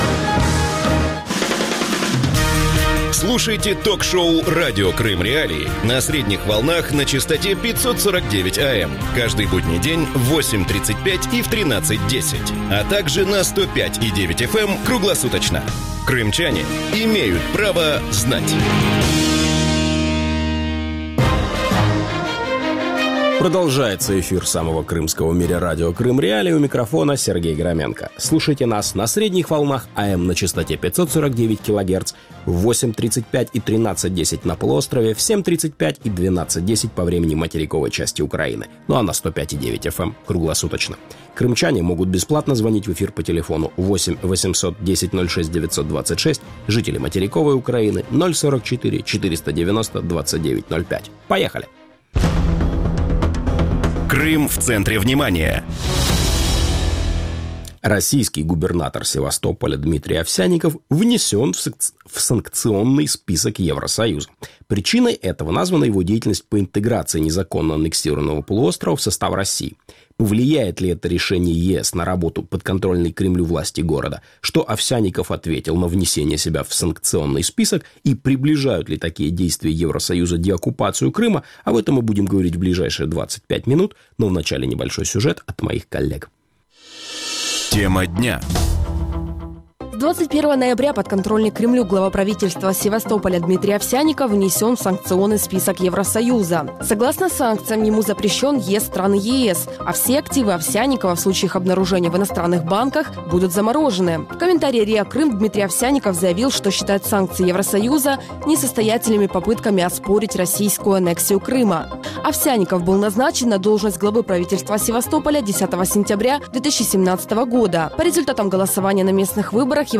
Радио Крым.Реалии эфире 24 часа в сутки, 7 дней в неделю.